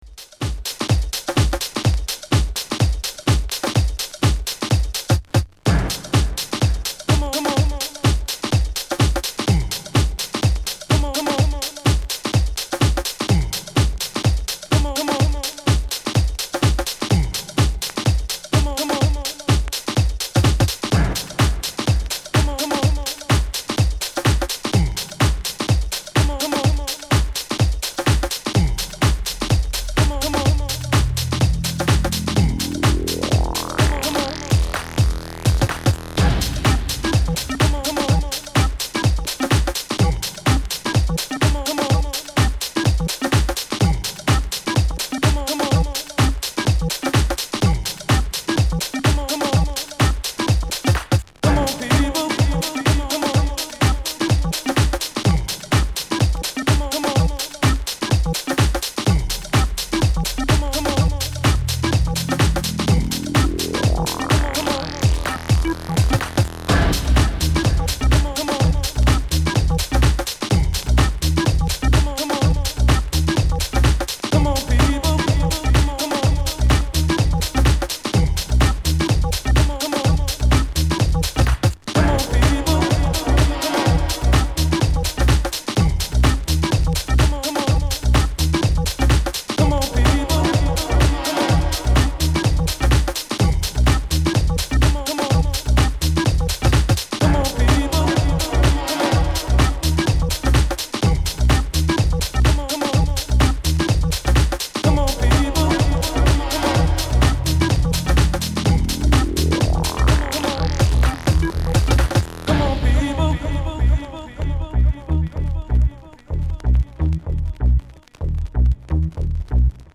House / Techno
Summer Dub